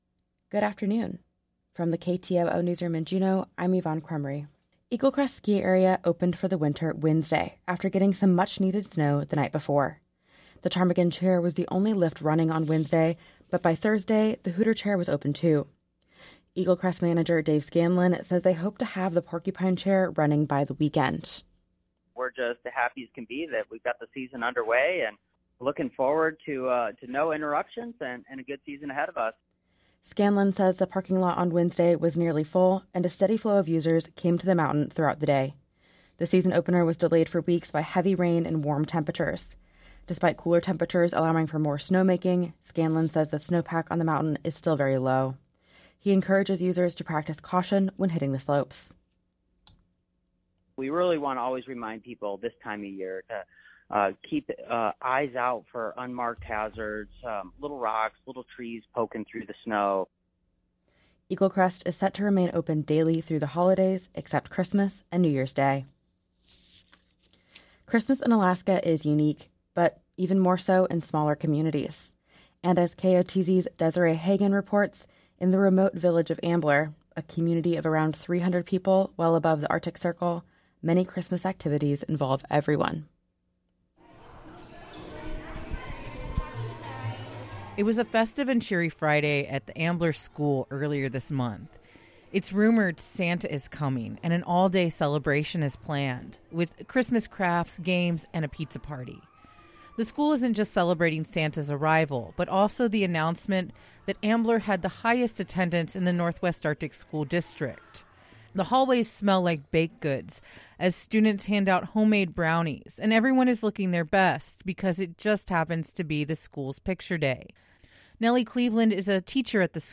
Newscast Friday, Dec. 22 2023